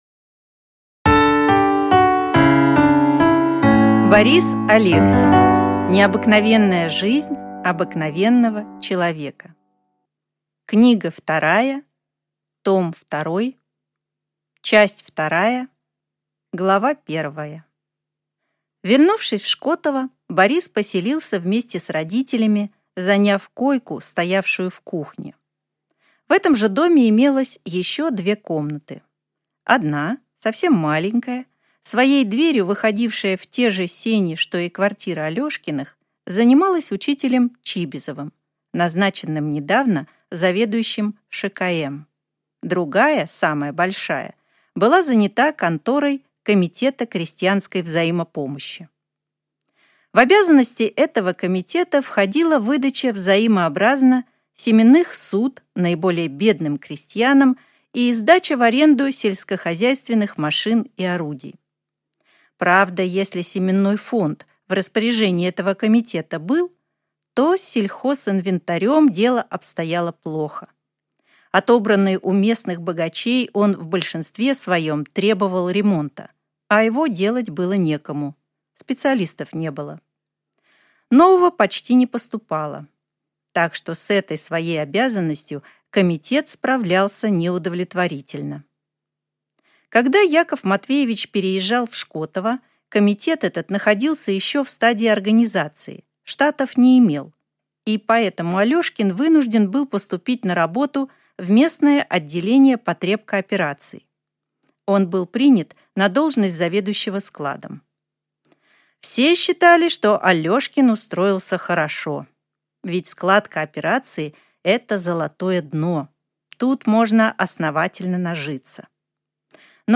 Аудиокнига Необыкновенная жизнь обыкновенного человека.